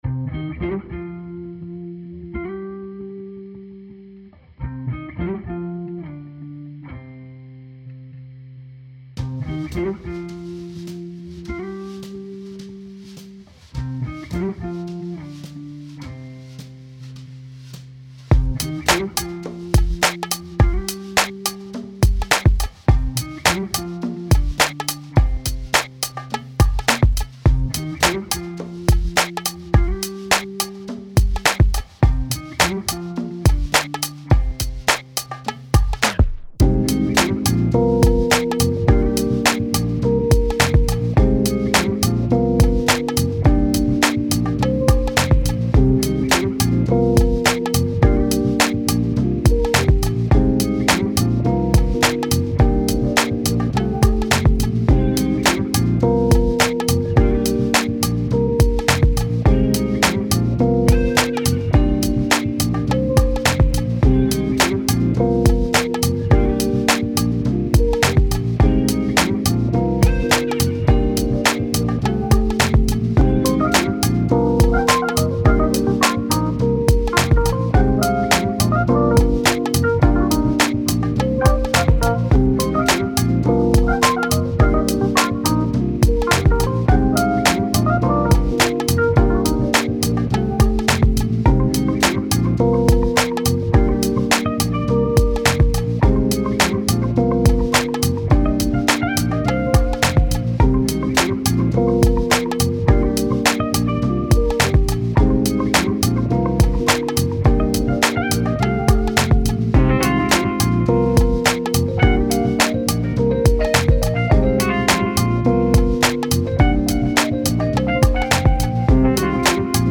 Untitled_No.1：AORっぽい何か（いくつかの音源はSpliceより）
Untitled_No.2：Tech Houseっぽいリズム・トラック（付属ループを５つ利用してます）